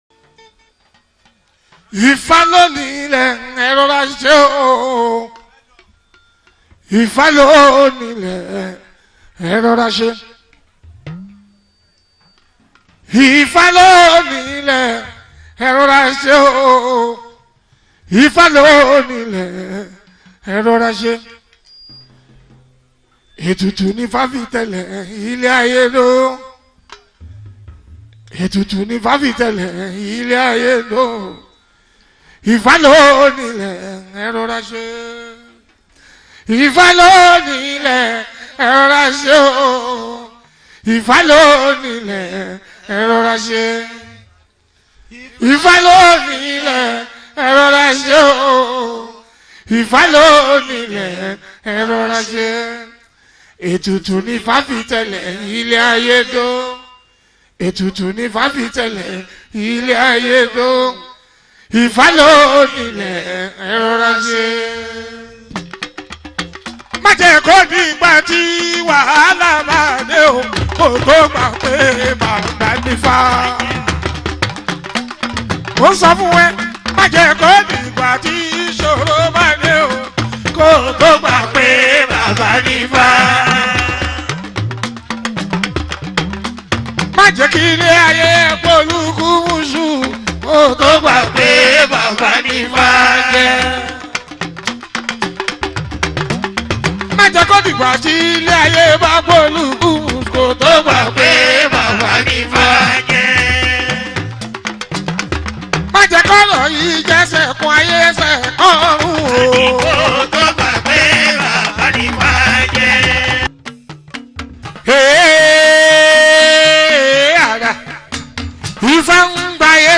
Nigerian Yoruba Fuji track
especially if you’re a lover of Yoruba Fuji Sounds